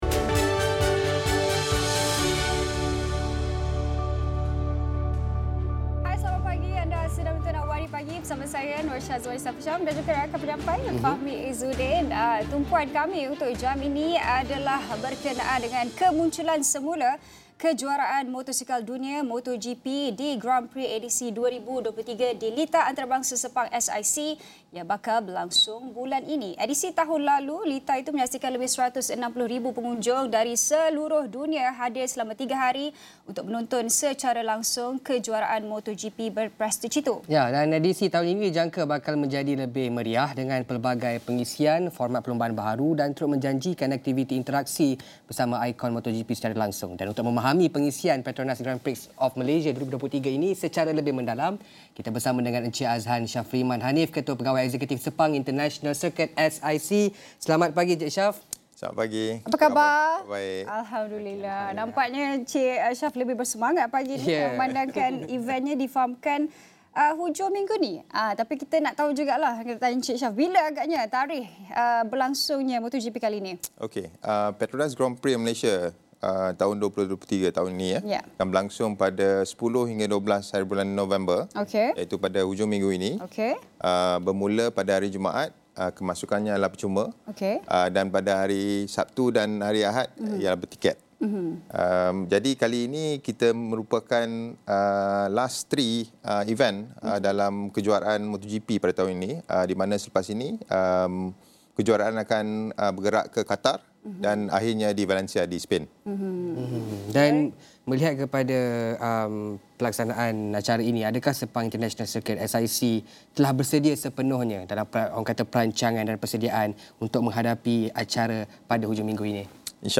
temu bual